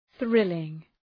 Προφορά
{‘ɵrılıŋ}